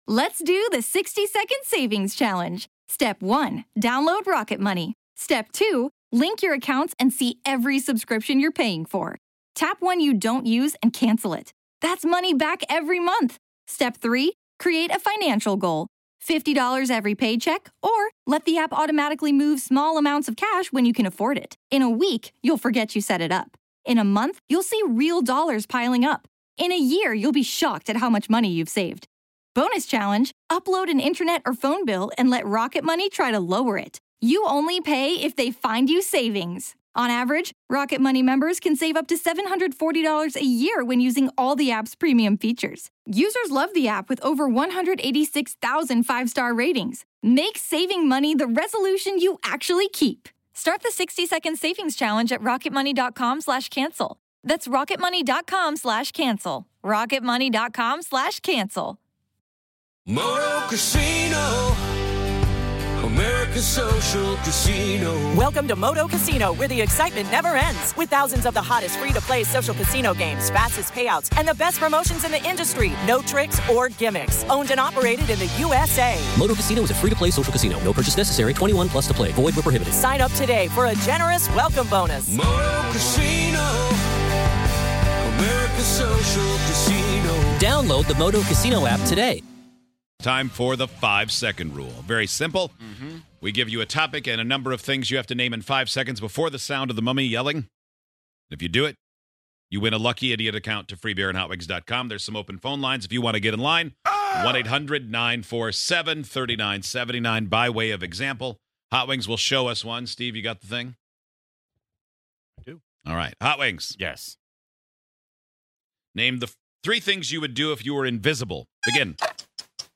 On today's show, let's play The 5 Second Rule! Can you beat the buzzer and get your answers in before the mummy sound??